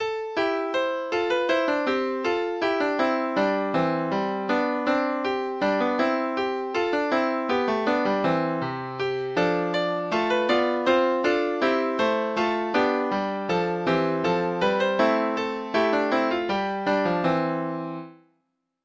Denne melodien er laga til originalteksta, den moderniserte versjonen har litt annan rytme.
Barnenytt�rs�nsker, Thomas Beck - last ned nota Lytt til data-generert lydfil Denne melodien er laga til originalteksta, den moderniserte versjonen har litt annan rytme.